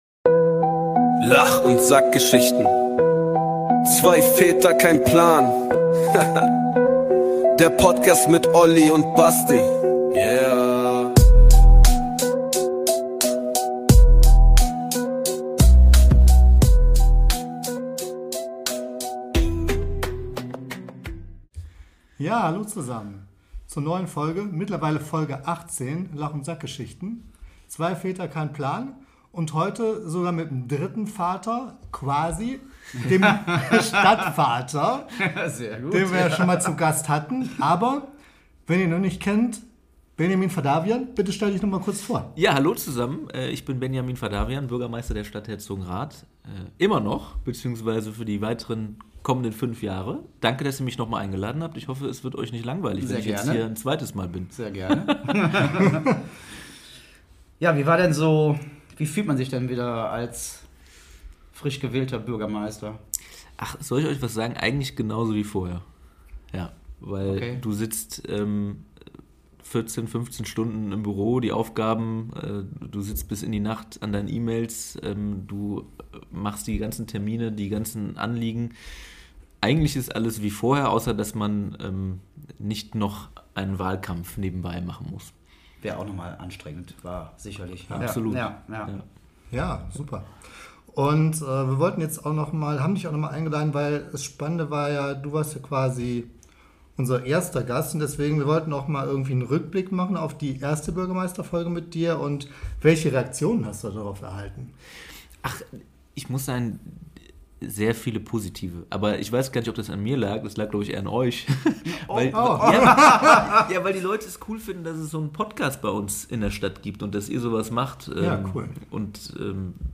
Herausgekommen ist eine sehr unterhaltsame Folge in entspannter Atmosphäre. Es geht unter anderem um seine Eindrücke rund um seinen ersten Besuch bei uns, worüber er selbst gerne mal einen Podcasts machen würde, den Fortschritt des Herzogenrather Hallenbades und wir erfahren, warum Bürgermeister auch im Anzug cool sein können.